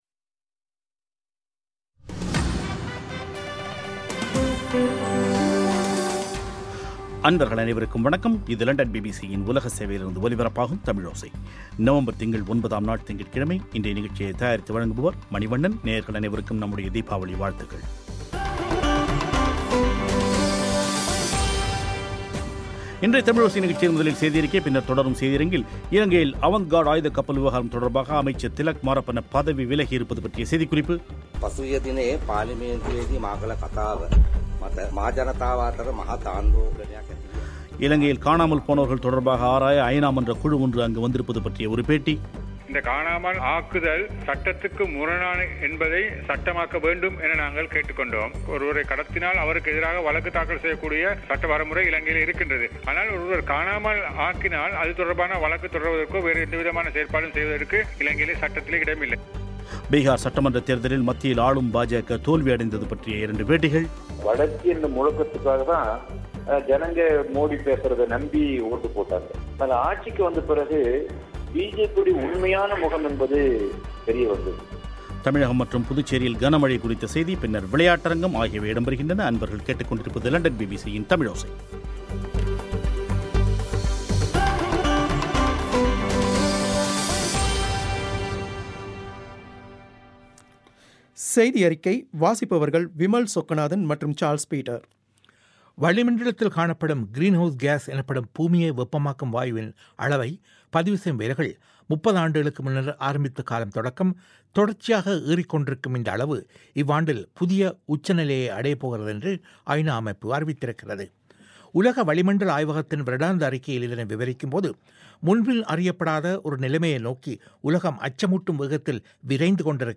இலங்கையில் காணாமல் போனவர்கள் தொடர்பாக ஆராய ஐநா மன்ற குழு ஒன்று இலங்கை வந்திருப்பது பற்றிய ஒரு பேட்டி